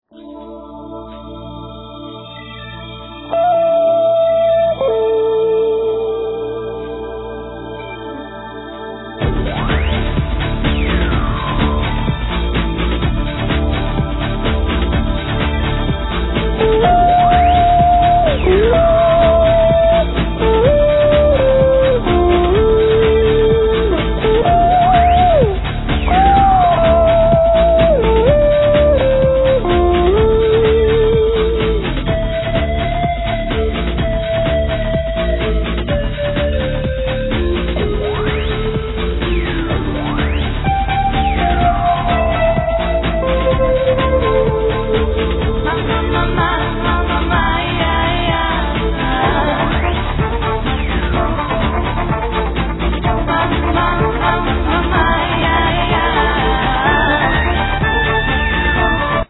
Vocals, Pipes
Flute, Pipe, 7-hole flute, Tambourine, Vocals
Acoustic guitar
Tarogato (Oboe-like shawm)
Doromb (mouth harp)